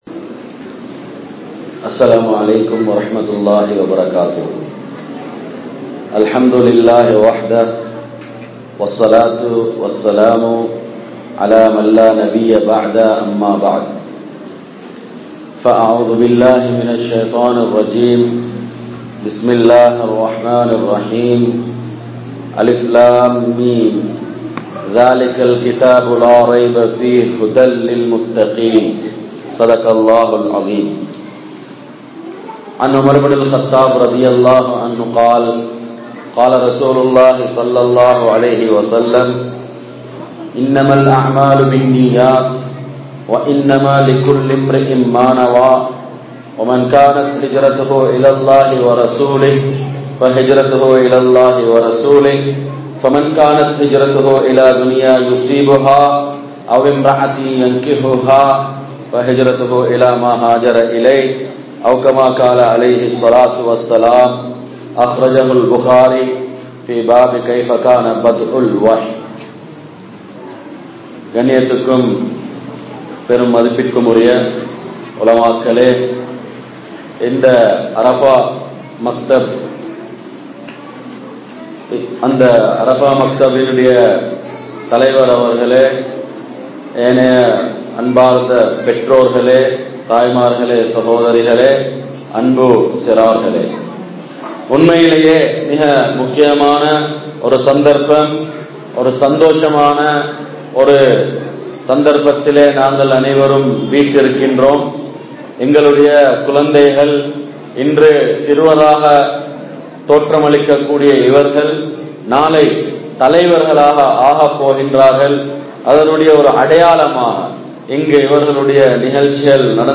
Pillaihalai Valikedukum Pettroarhal (பிள்ளைகளை வழிகெடுக்கும் பெற்றோர்கள்) | Audio Bayans | All Ceylon Muslim Youth Community | Addalaichenai